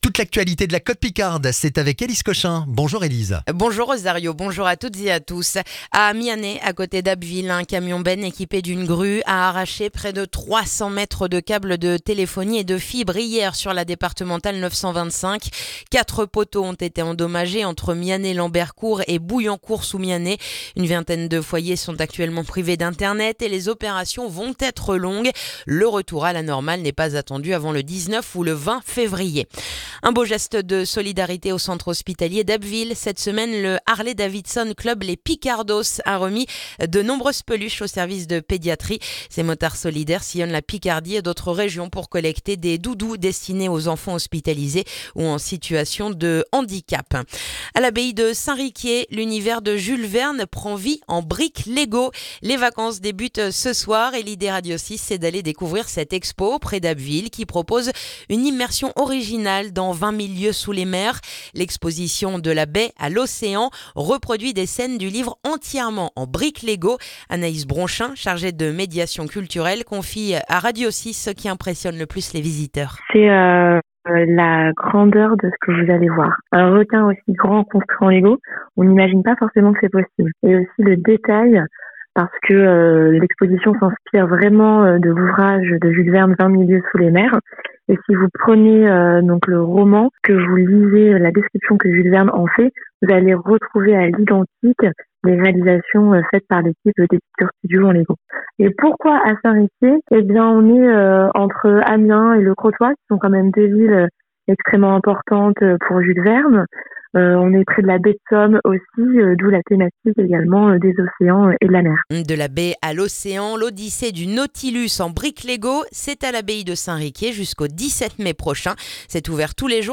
Le journal du vendredi 13 février en Baie de Somme et dans la région d'Abbeville